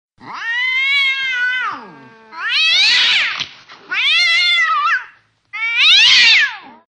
• Animal Ringtones